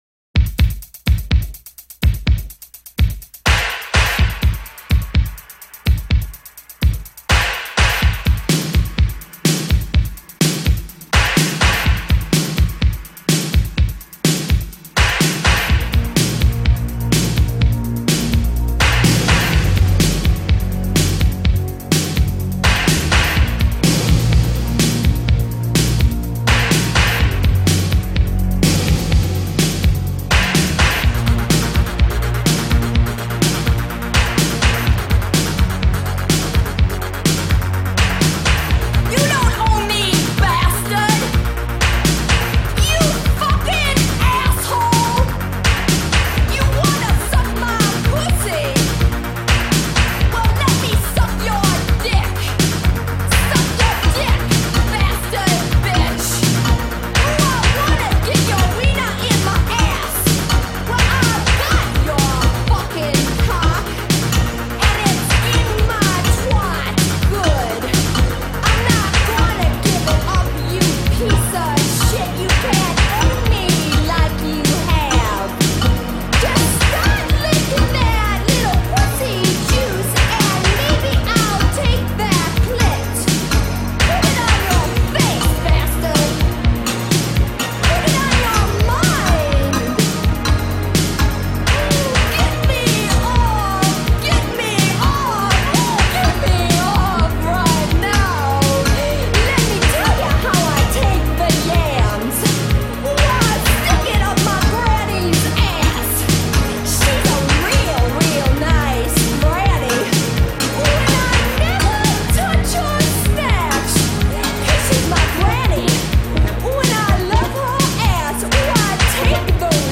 Un bon flow d'insultes qui donne la pêche pour la journée.